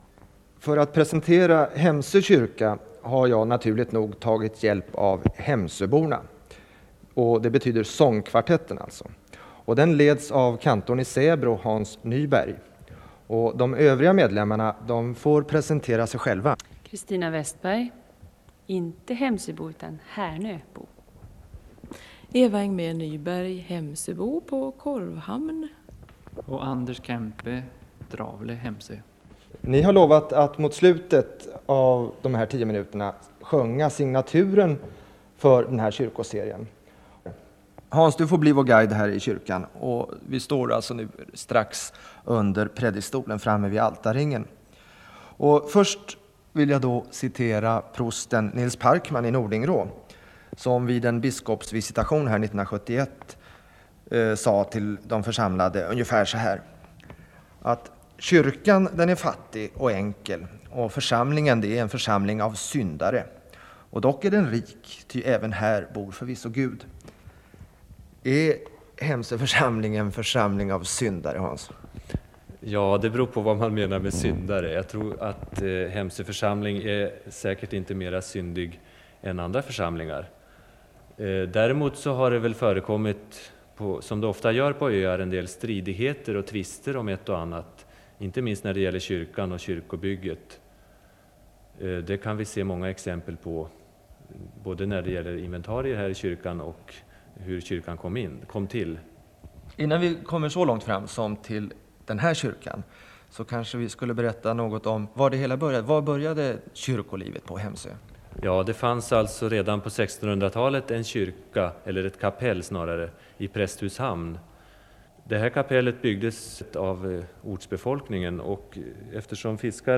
Hemsöborna avslutar programmet med att sjunga psalmen "Den signade dag", som också är signatur till den här programserien.
Källa: Sveriges Lokalradio AB Västernorrland